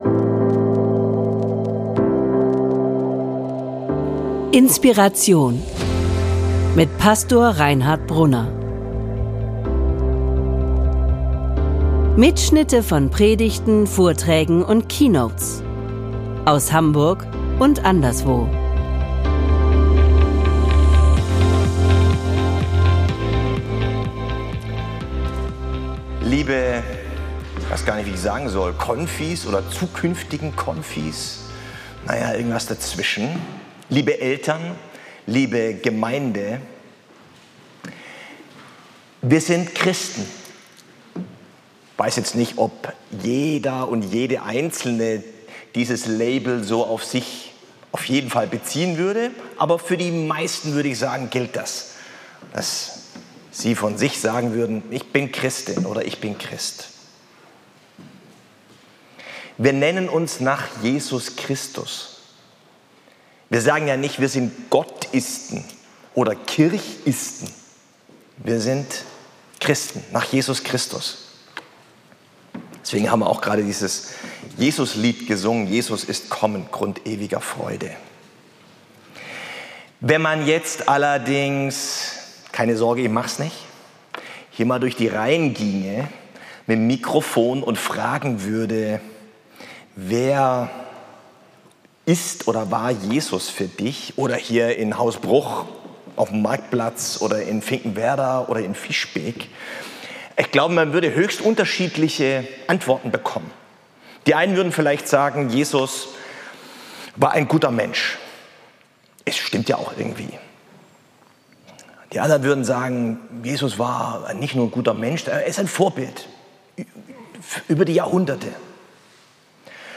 Diese Predigt, gehalten beim Startgottesdienst zur Konfi-Zeit 2024/25, gibt einen kleinen Einblick in die Thematik über eine EInladung zur Glaubensreise mit Jesus.